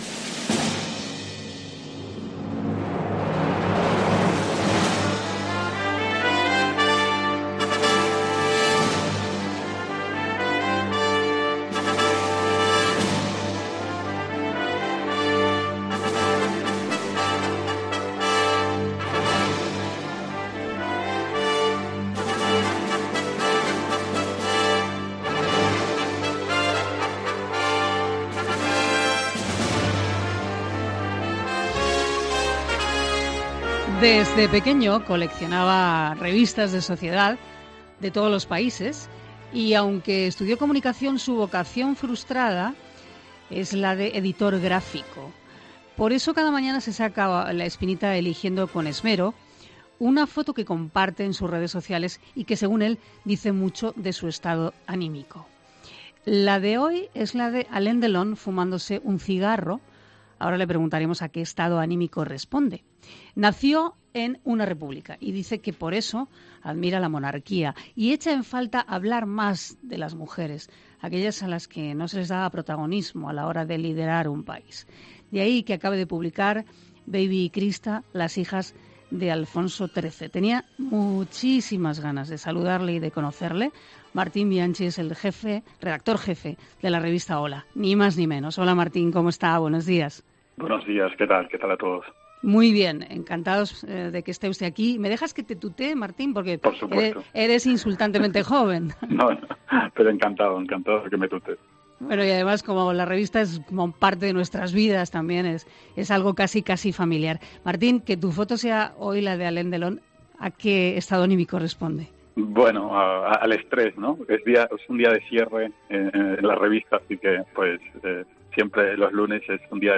La de hoy, cuando le hacemos esta entrevista, es de Alain Delon fumándose un cigarro, y nos dice en Herrera en COPE que responde al estrés, una etapa de estrés por la cantidad de responsabilidad y trabajo. Nació en la República y dice que por eso admira la Monarquía... y echa en falta hablar más de las mujeres, aquellas a las que no se daba protagonismo a la hora de liderar un país.